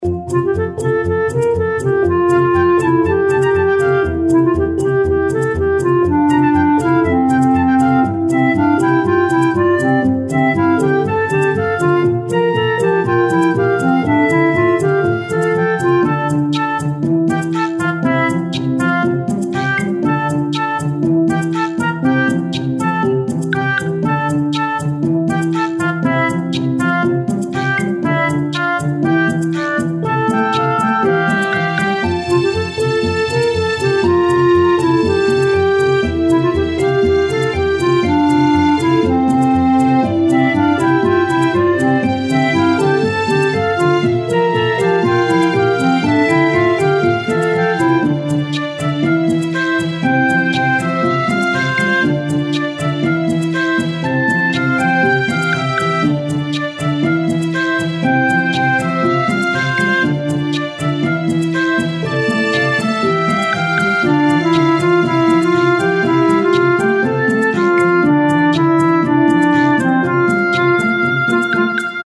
ファンタジー系。
這個很和平也不錯～